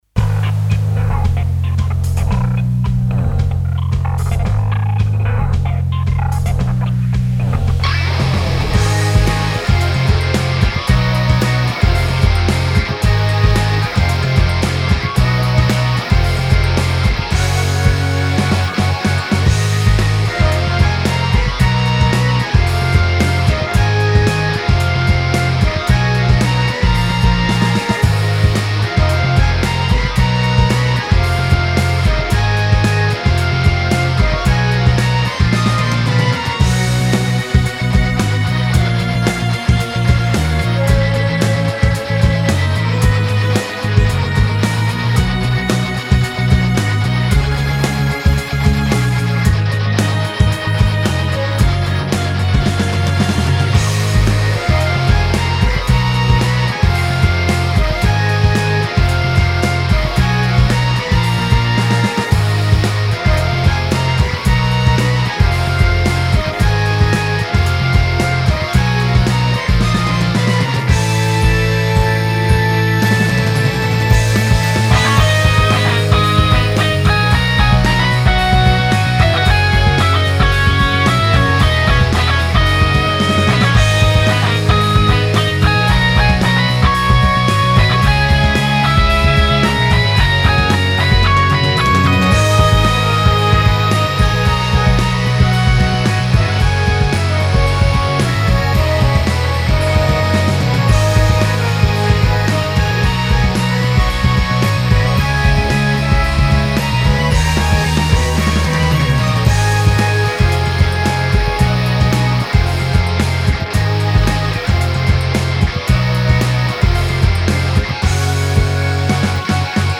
フリーBGM バトル・戦闘 バンドサウンド